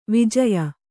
♪ vijaya